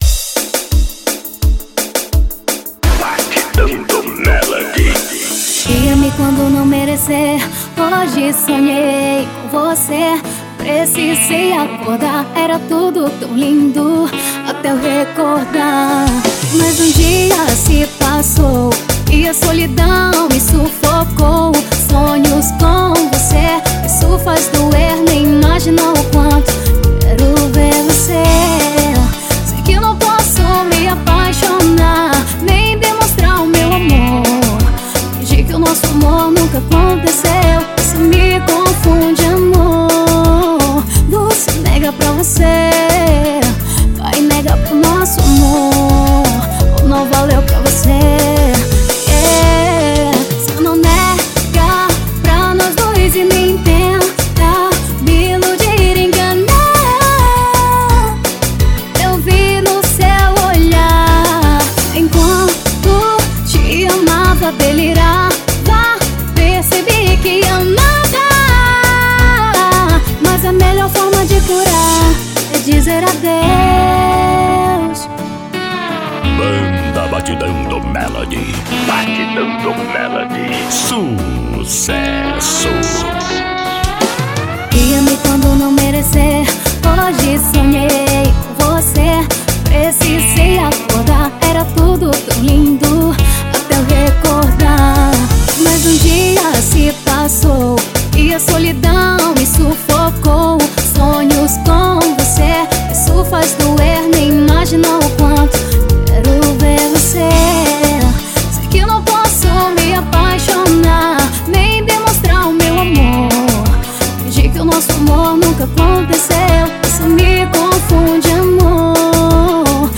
EstiloTecnobrega